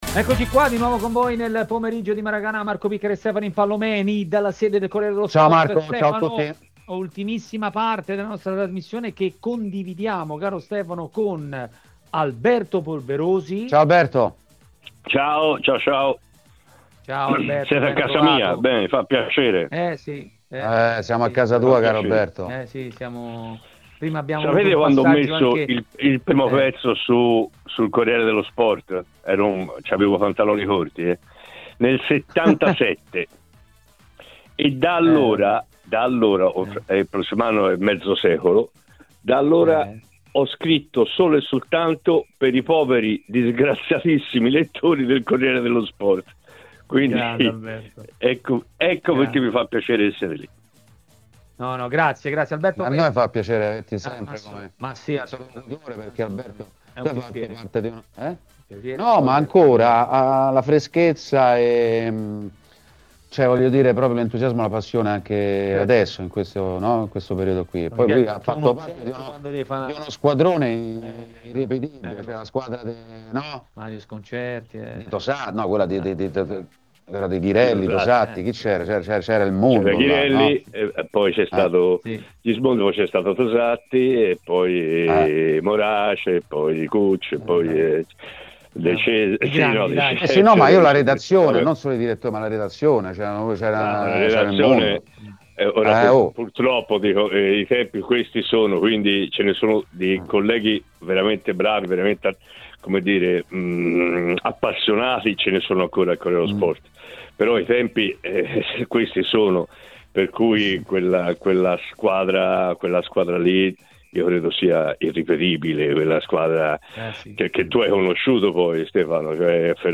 L'ex calciatore Alberto Di Chiara è stato ospite di TMW Radio, durante Maracanà.